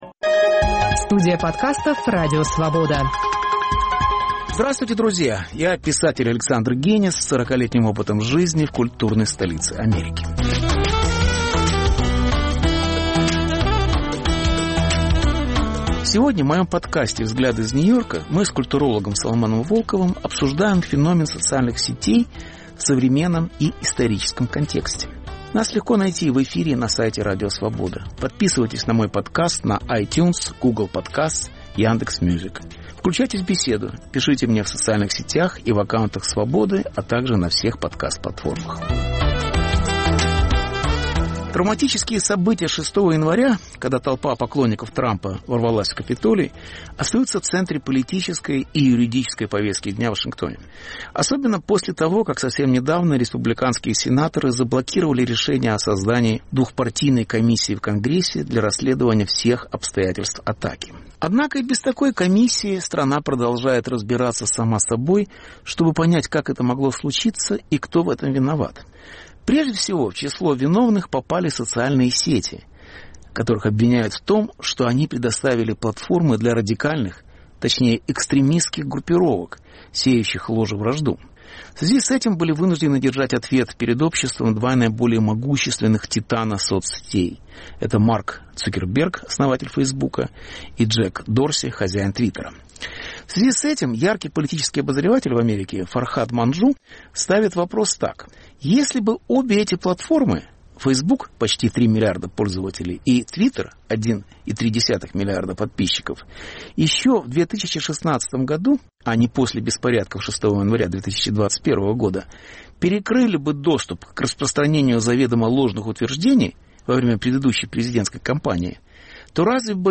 Беседа с Соломоном Волковым о сетевой культуре и ее исследователях